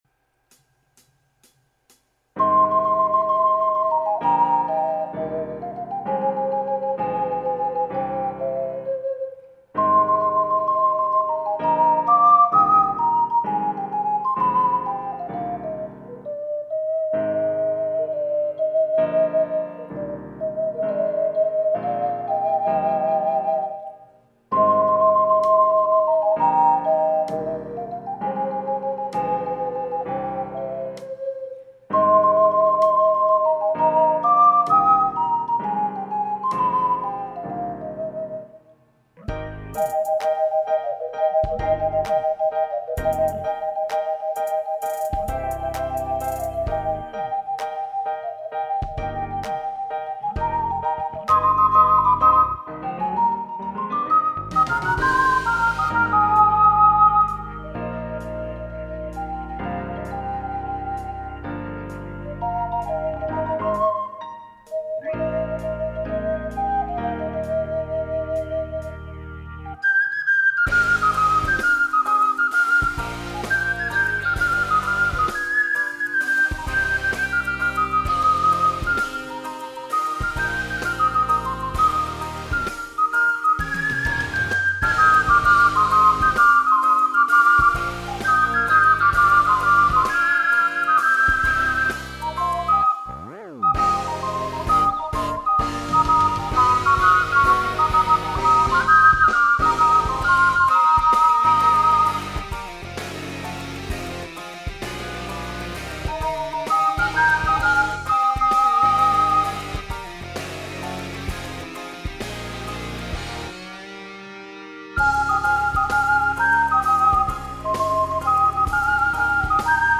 ＊試奏は雑に録音・編集したので、参考程度と思ってください。
(1)2ndは1～30小節はAG管で吹きました。。